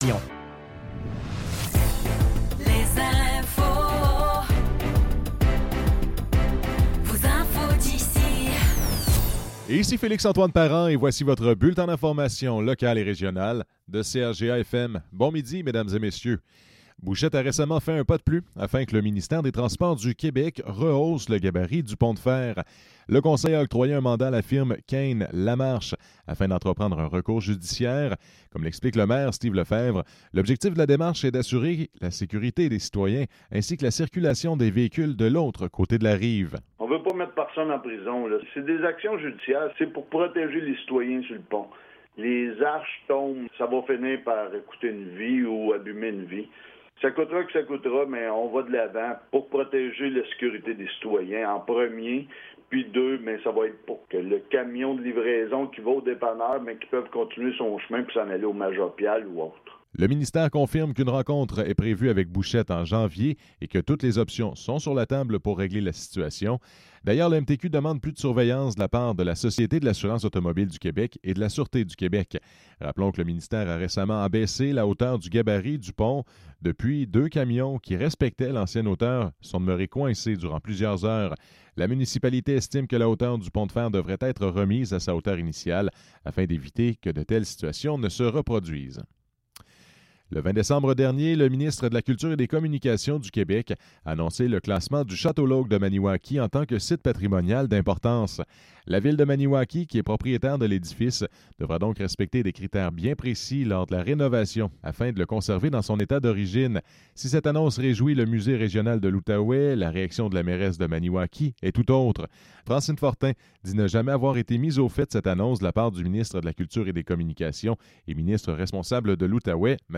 Nouvelles locales - 26 décembre 2024 - 12 h